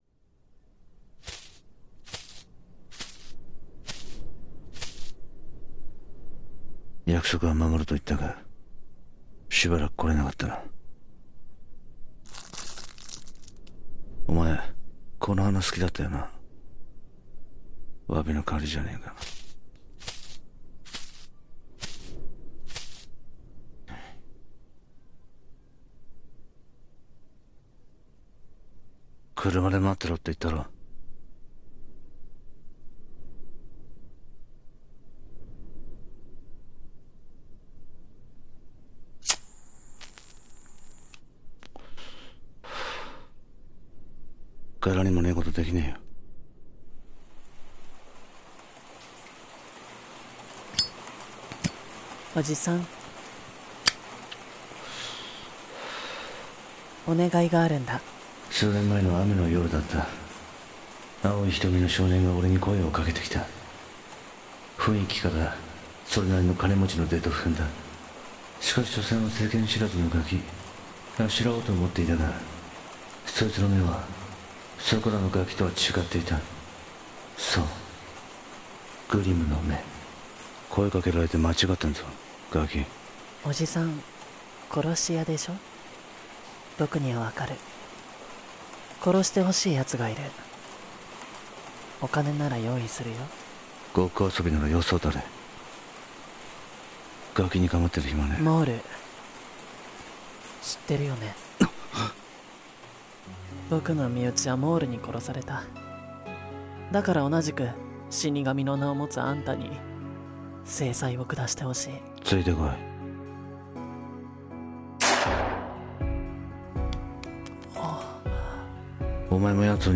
Jasper-Episode of GLIM CHILD-【三人声劇台本